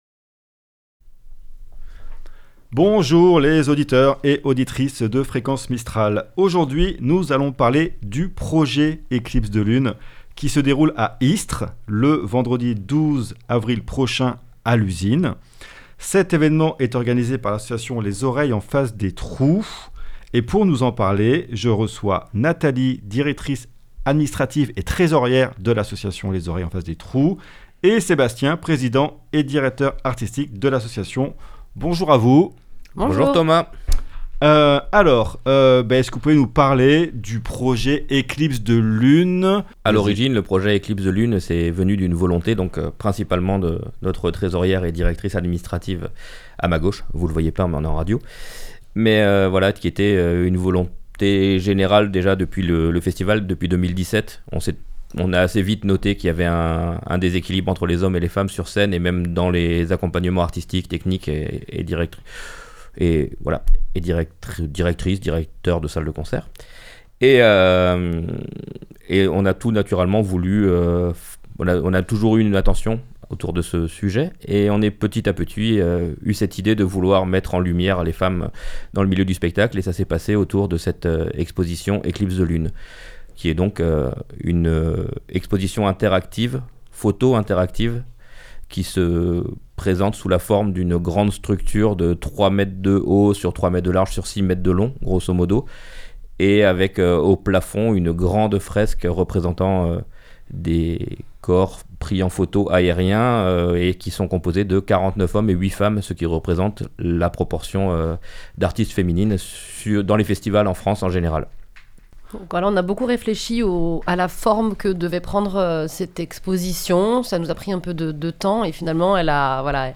au micro de Fréquence Mistral pour nous en parler